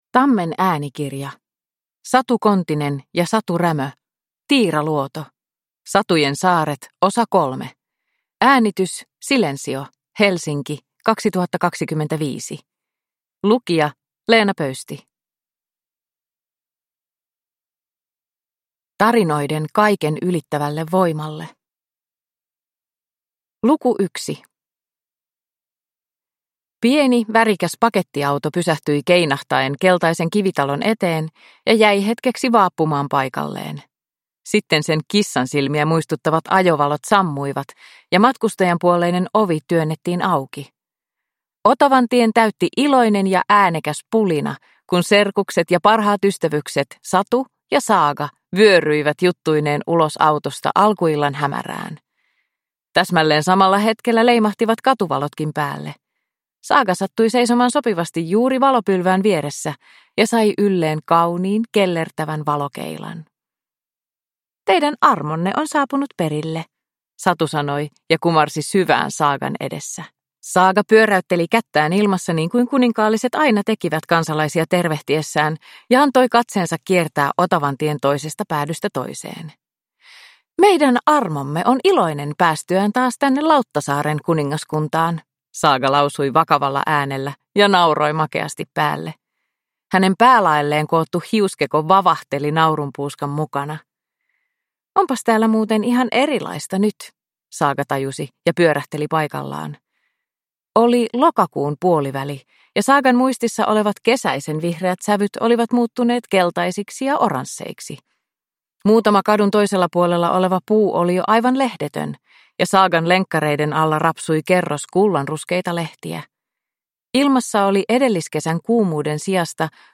Tiiraluoto – Ljudbok